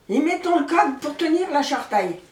Mots Clé foin, fenaison ; Localisation Falleron
Langue Maraîchin
Catégorie Locution